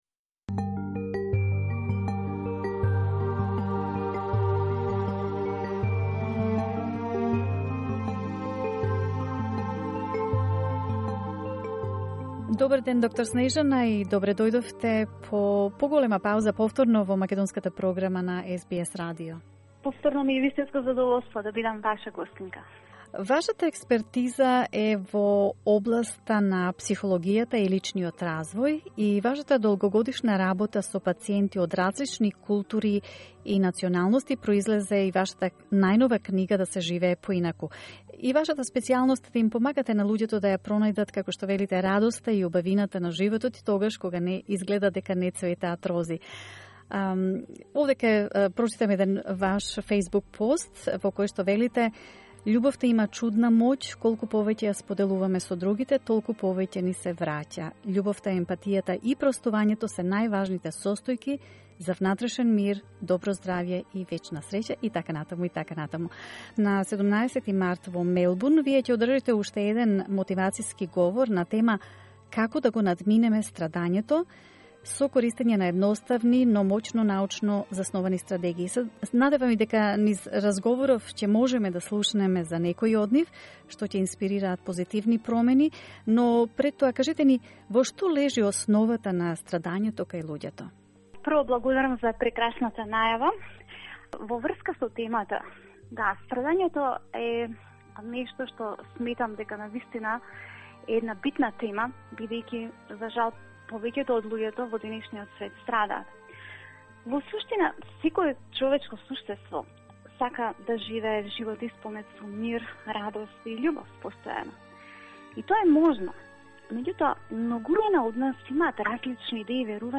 Motivational session in Macedonian in Melbourne